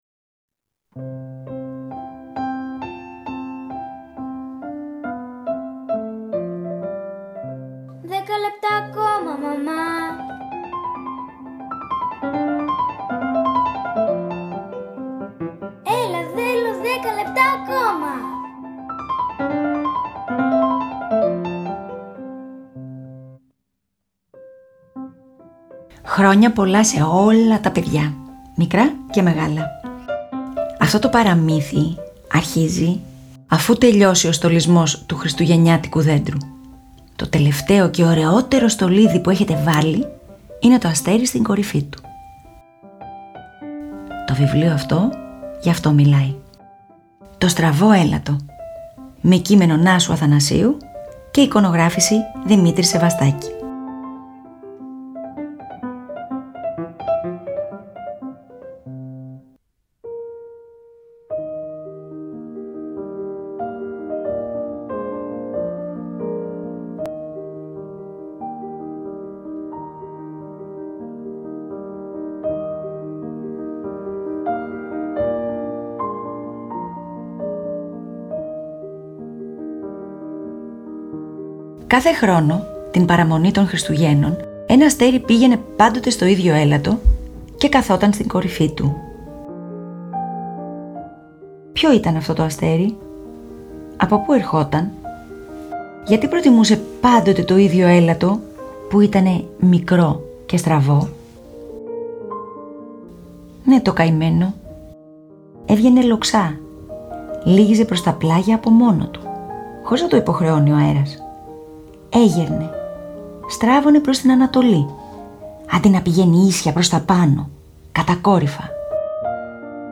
αφηγούνται ένα τρυφερό παραμύθι